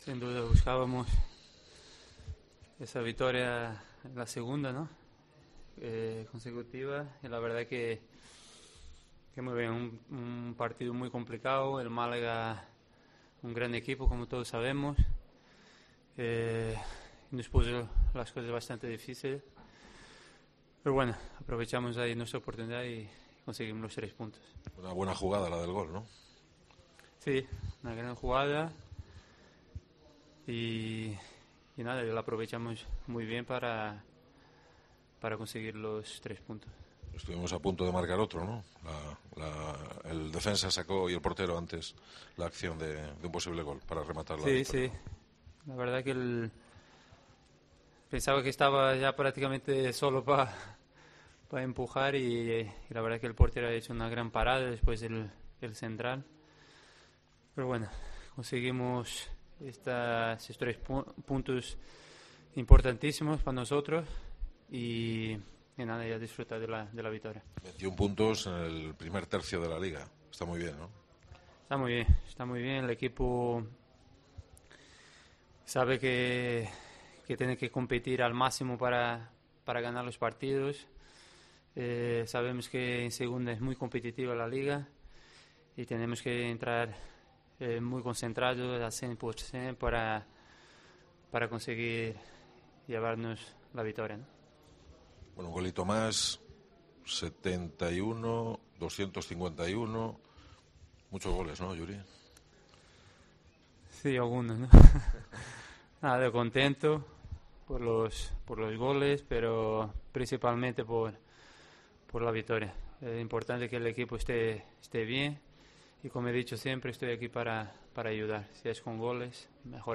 POSTPARTIDO
Escucha aquí las palabras del delantero de la Ponferradina, Yuri, tras la victoria 1-0 ante el Málaga